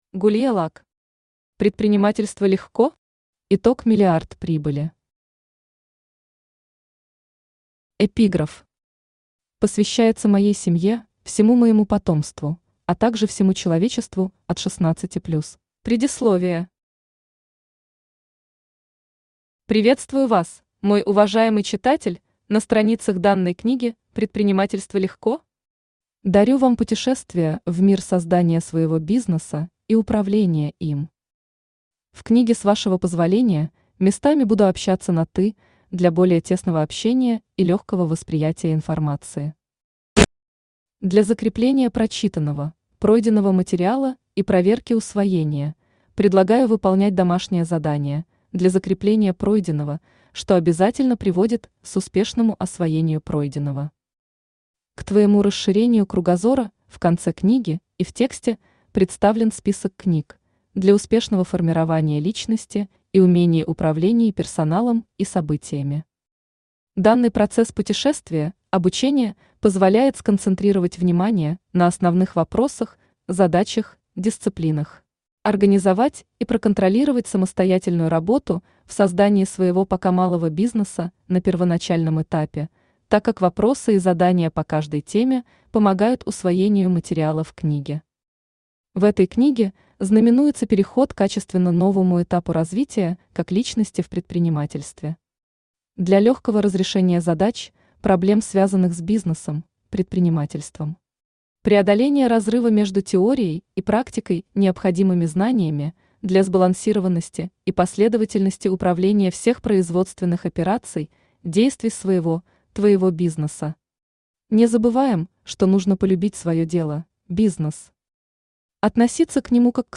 Аудиокнига Предпринимательство легко?! Итог миллиард прибыли!